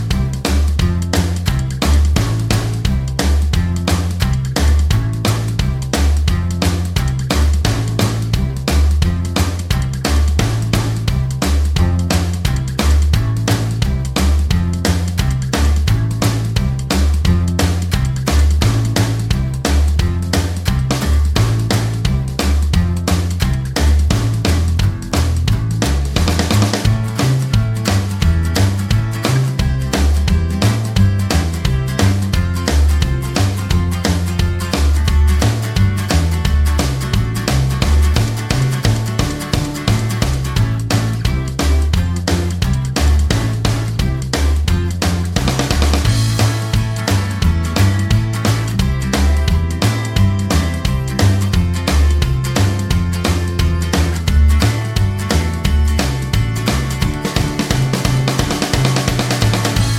Minus Main Guitars For Guitarists 2:47 Buy £1.50